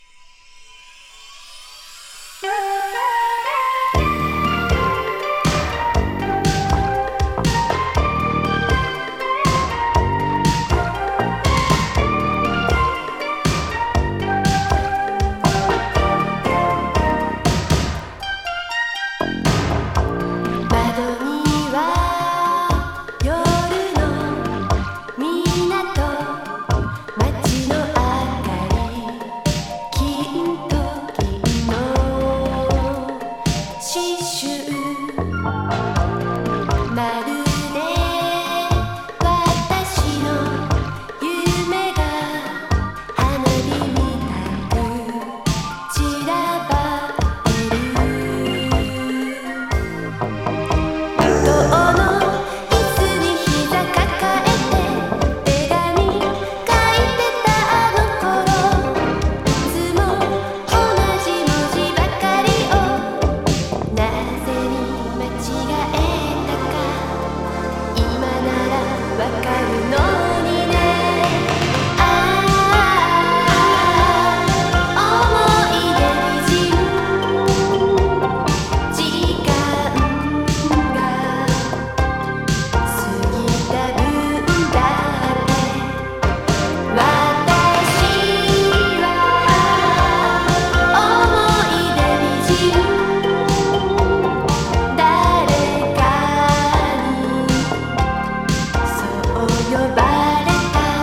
イントロから即、耳を奪われるオリエンタル歌謡。